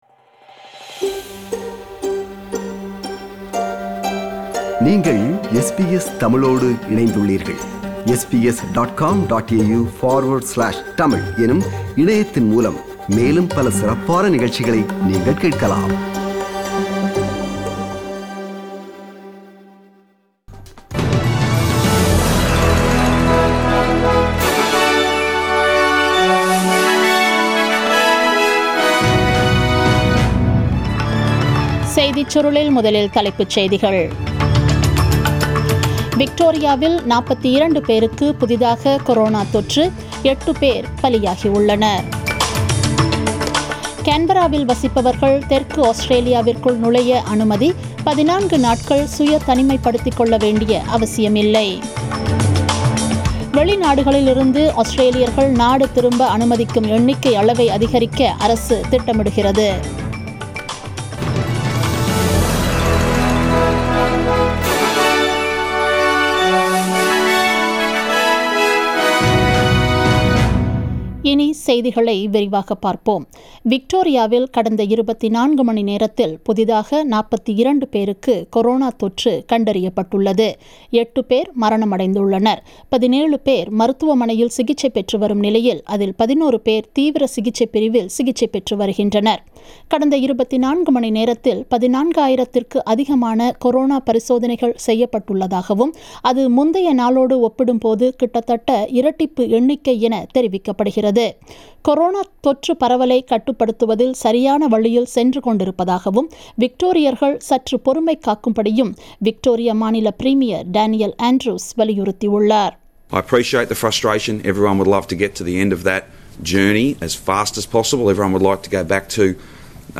The news bulletin was aired on 16 September 2020 (Wed) at 8pm.